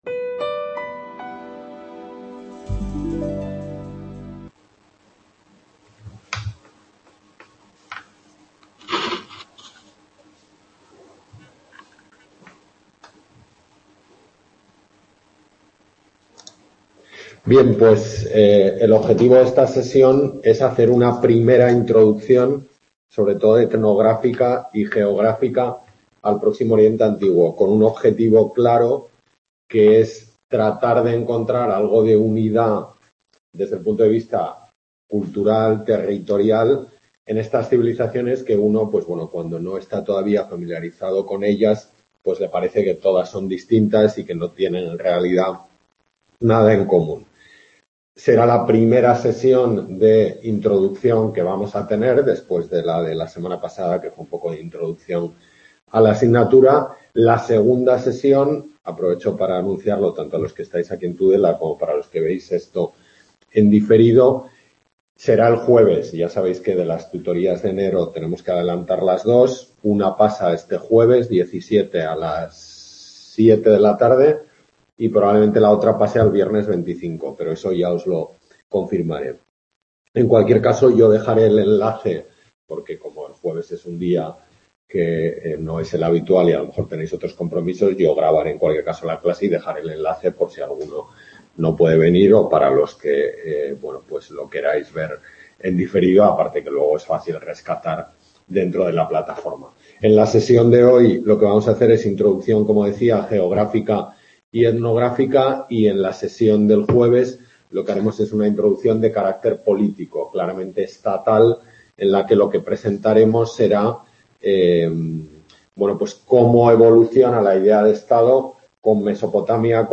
Tutoría de la asignatura Historia Antigua I